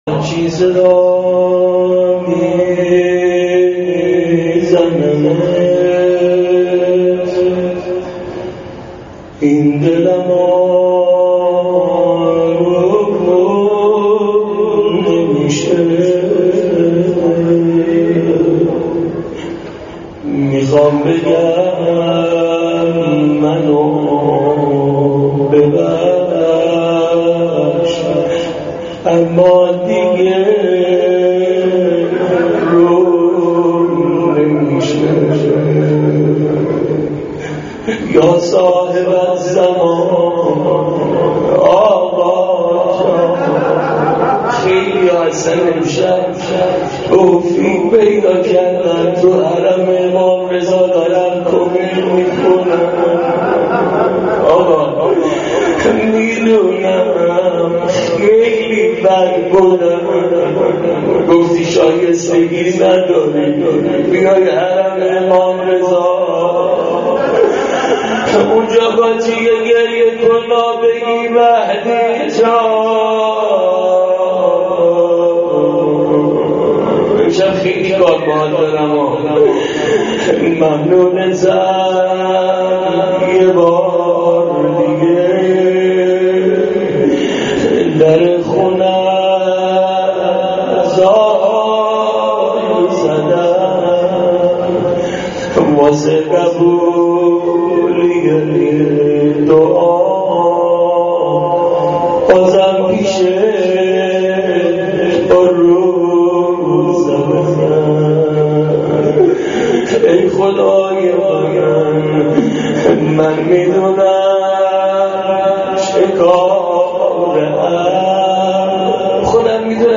مناجات با خدا توسل به امام زمان و امام رضا قبل از کمیل.mp3